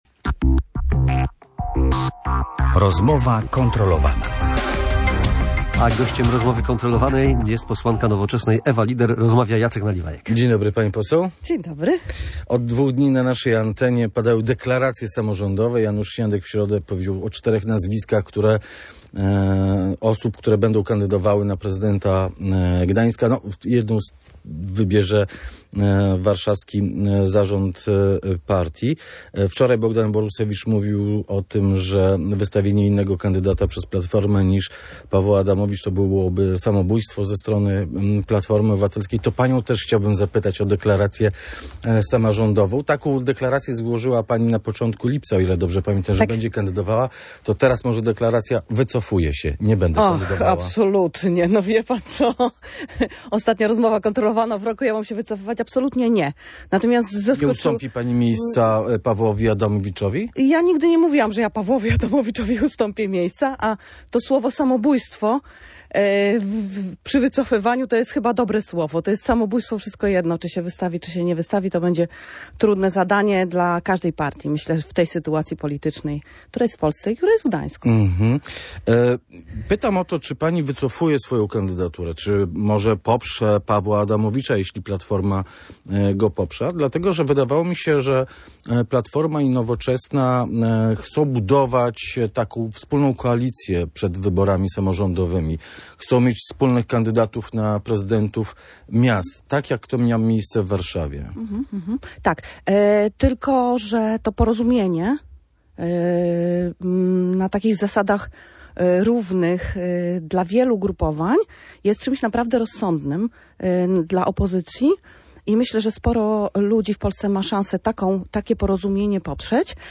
- Nie sądzę, żeby Platforma Obywatelska poparła kandydaturę Pawła Adamowicza - mówiła w Rozmowie Kontrolowanej Ewa Lieder.